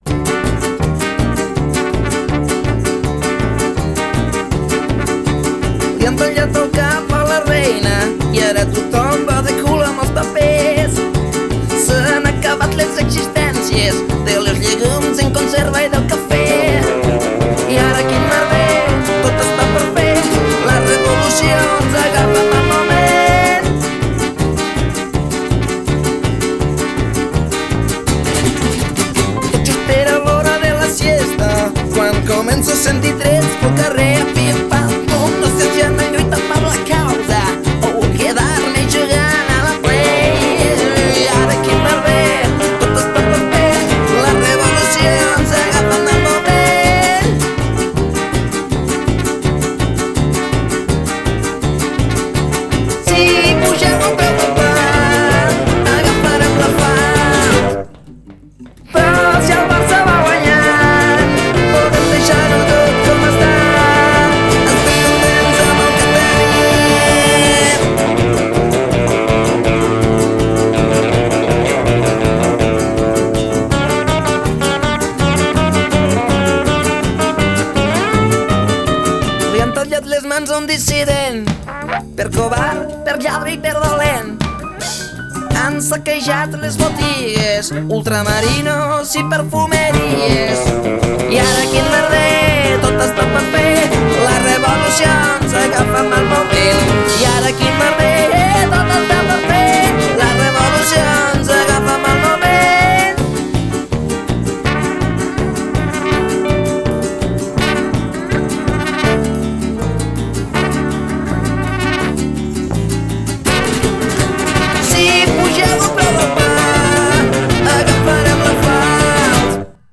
Jazz o rockabilly? Pop o rock? Rumba o folk?